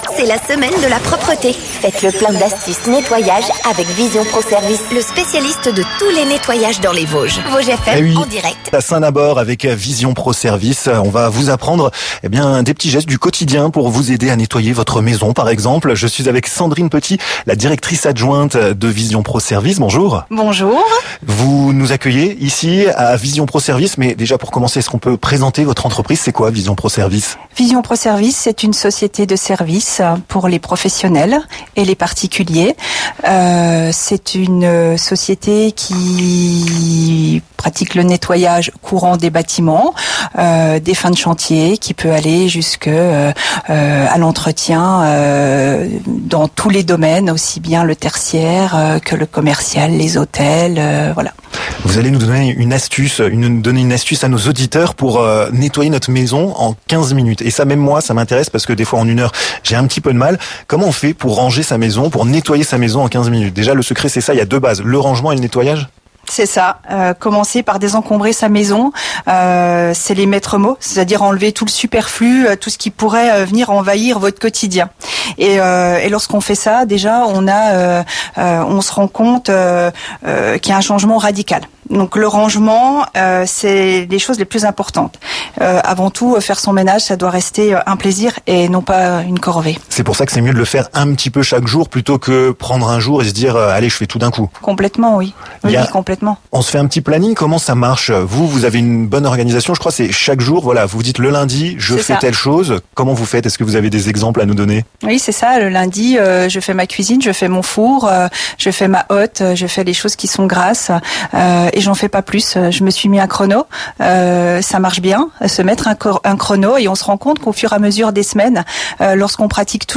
Arriver à bout des différentes tâches, réaliser ses produits ménagers écologiques et économiques, quelles précautions prendre pour éviter les accidents domestiques, ou encore comment avoir une maison propre en 15 minutes par jour? Toutes les réponses avec nos intervenants lors de cette émission spéciale chez Vision Pro Services à Saint-Nabord!